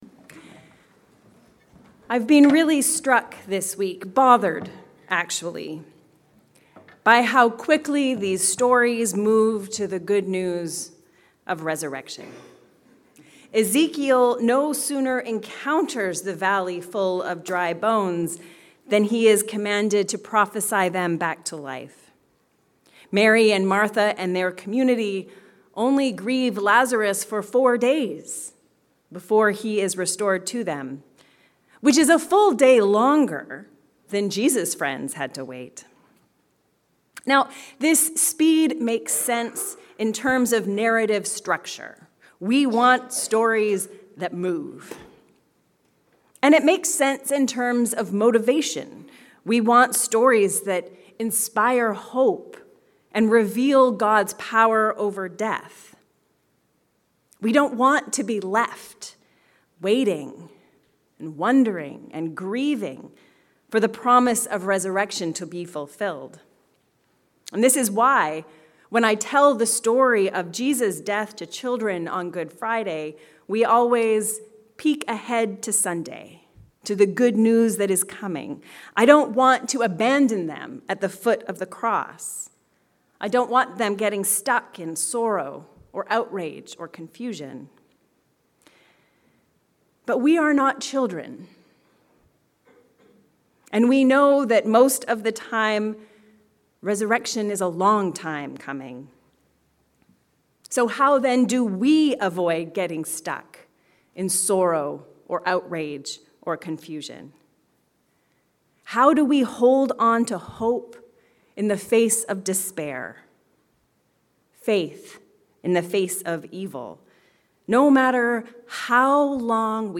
Before the Resurrection. A sermon for the Fifth Sunday in Lent